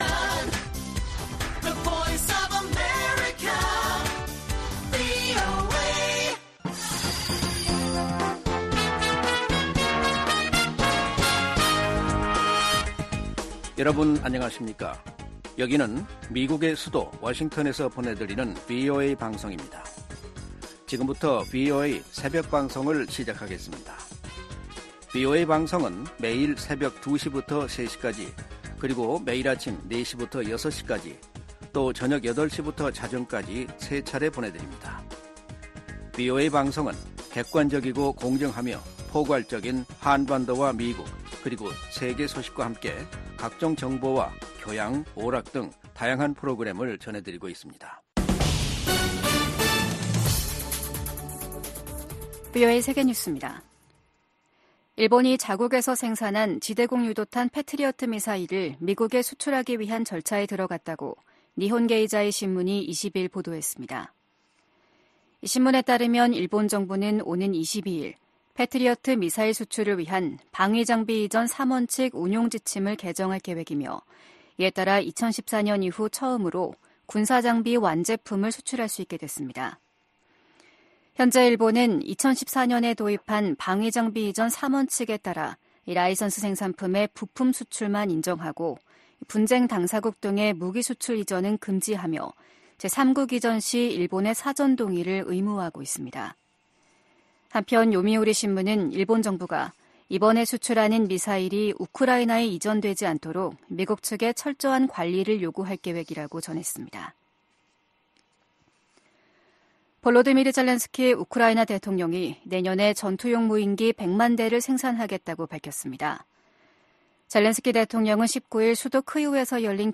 VOA 한국어 '출발 뉴스 쇼', 2023년 12월 21일 방송입니다. 유엔 안보리가 북한의 대륙간탄도미사일(ICBM) 발사에 대응한 긴급 공개회의를 개최합니다. 미 국무부는 중국에 북한의 개발 핵 야욕을 억제하도록 건설적 역할을 촉구했습니다.